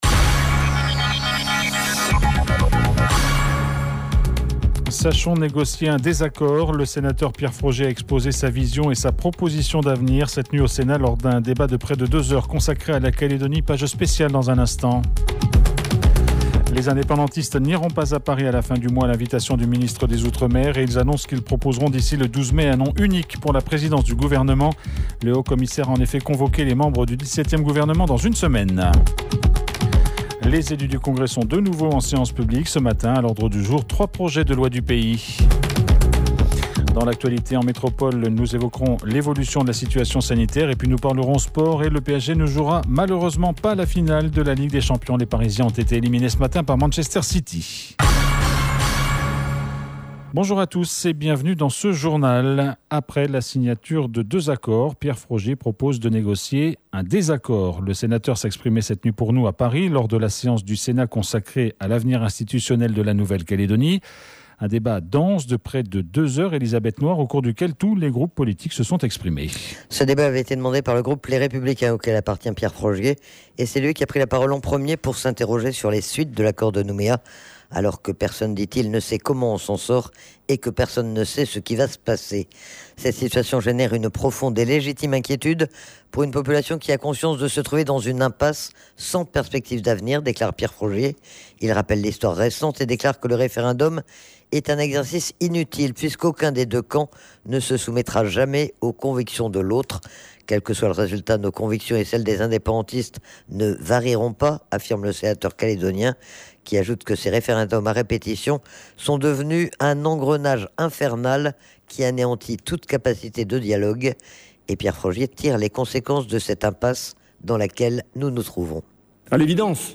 JOURNAL : MERCREDI 05/05/21 (MIDI)